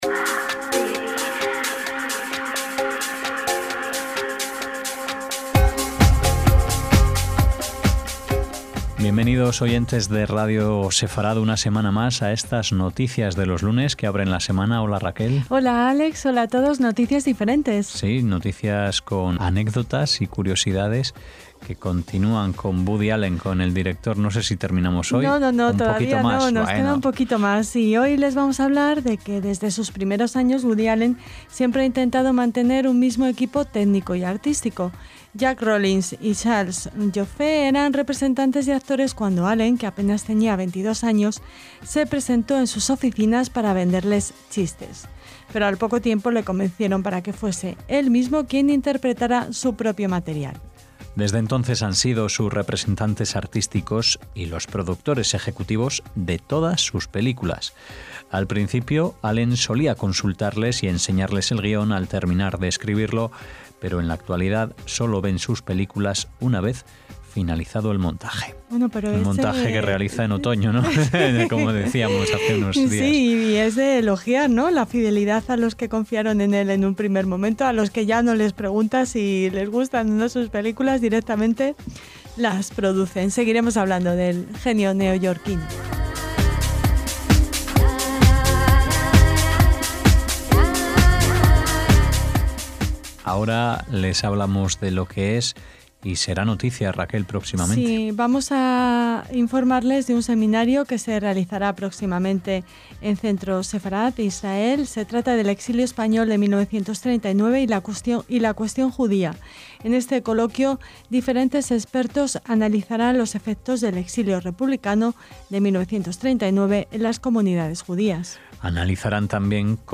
También les invitamos a recorrer nuestro archivo histórico de la mano y el saxo de Stan Getz.